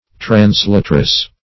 Translatress \Trans*lat"ress\, n. A woman who translates.